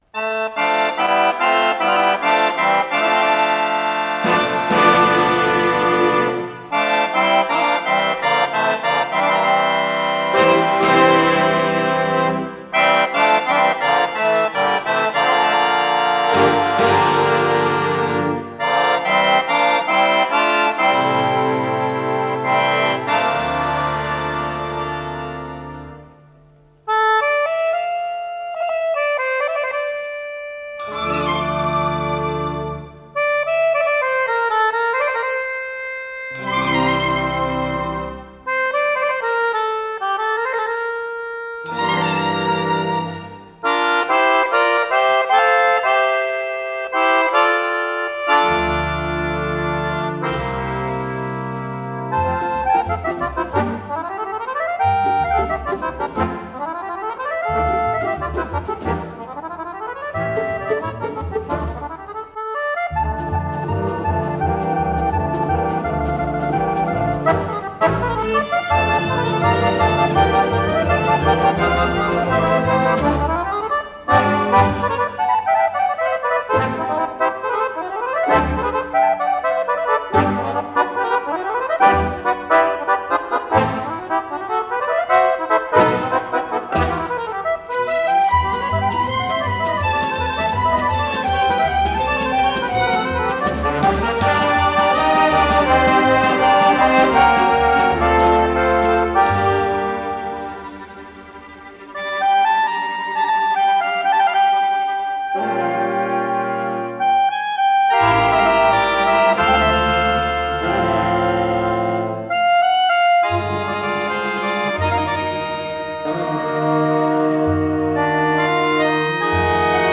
с профессиональной магнитной ленты
аккордеон